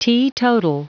Prononciation du mot teetotal en anglais (fichier audio)
Prononciation du mot : teetotal